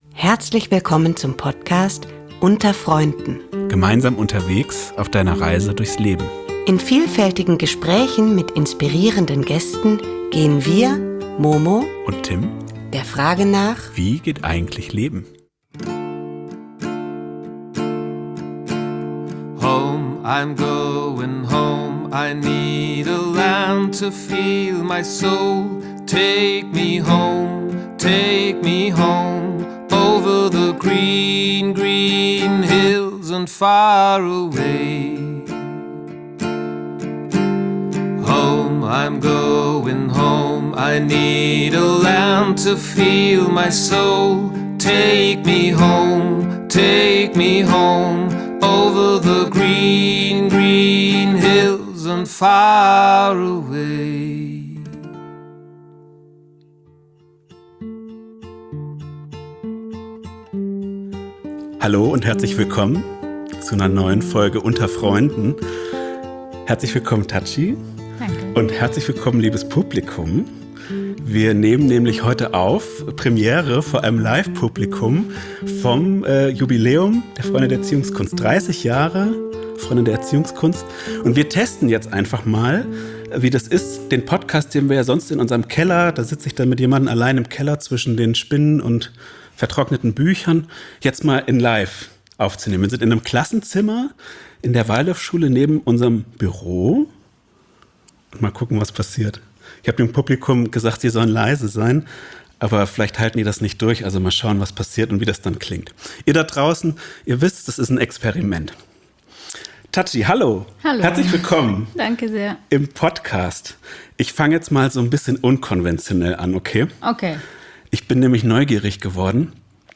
Das Interview wurde auf dem 30Jährigen Jubiläum der Freiwilligendienste der Freunde der Erziehungskunst aufgenommen, nicht auf dem Jubiläum der Freunde als Gesamtverein – den Gesamtverein gibt es schon länger als 30 Jahre.